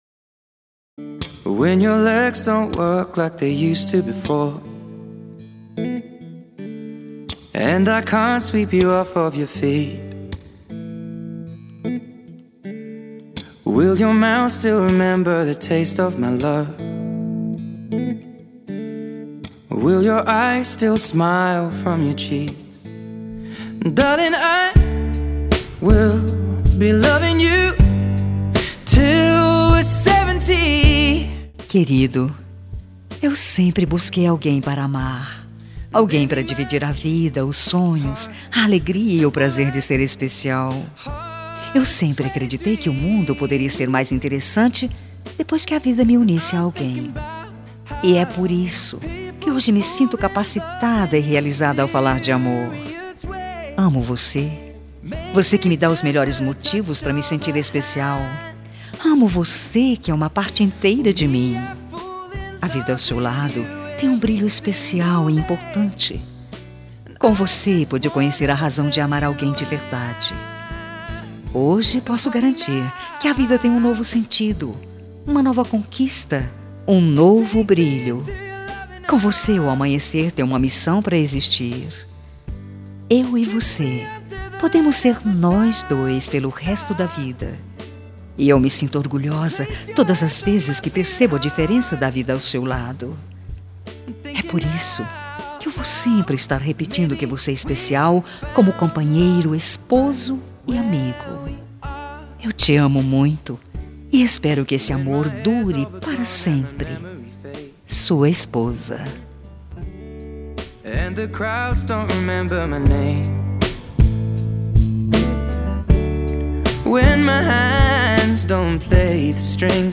Telemensagem Romântica para Marido – Voz Feminina – Cód: 9063